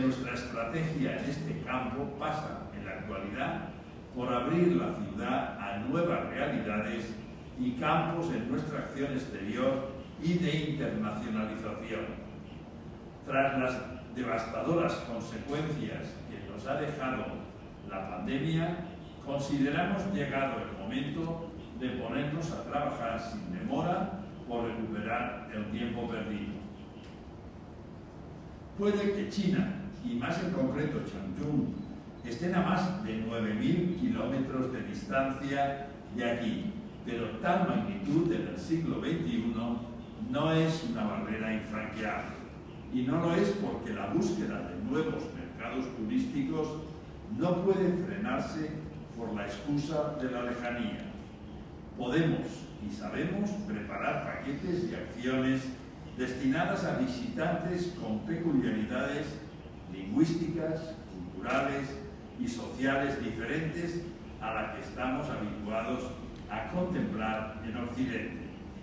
El alcalde de València, Joan Ribó, participa en la Jornada de Intercambio Turístico de Alto Nivel, con el alcalde de Changchún, Zhao Xian, organizado por el Instituto Confucio de la Universidad de València